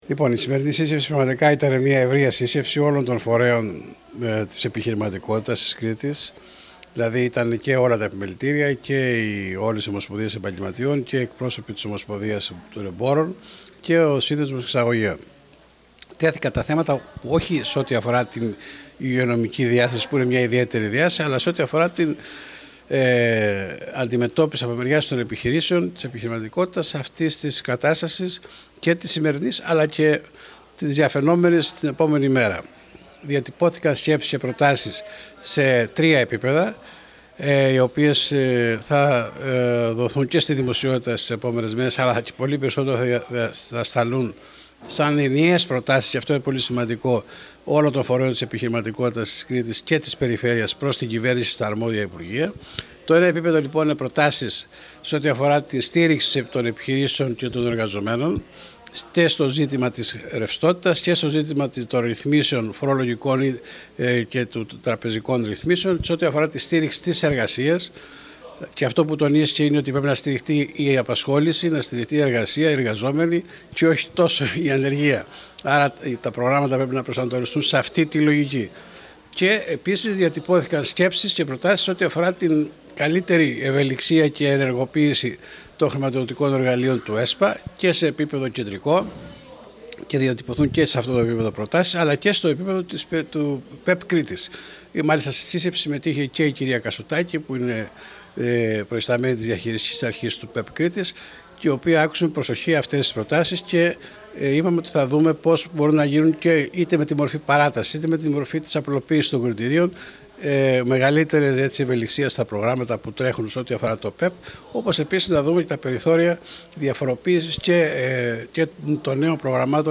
Ακούστε τις δηλώσεις του Αντιπεριφερειάρχη Επιχειρηματικότητας Αντώνη Παπαδεράκη: